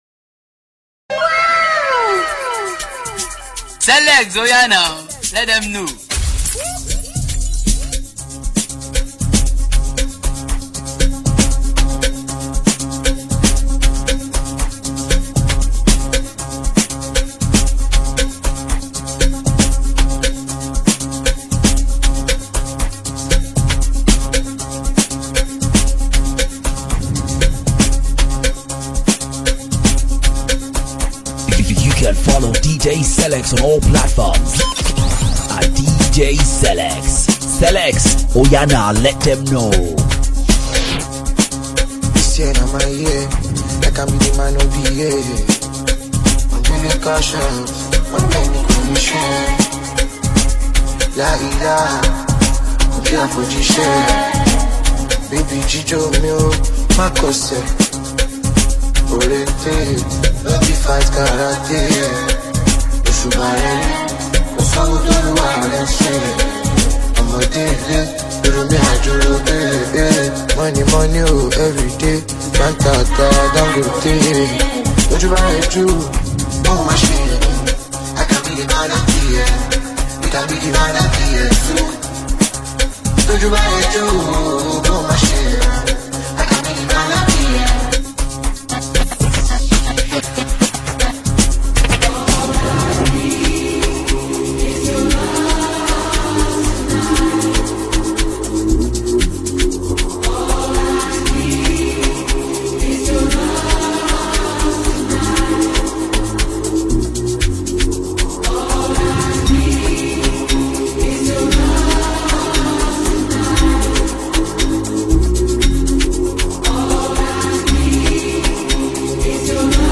This dj mix consist of trending hit songs in 2025.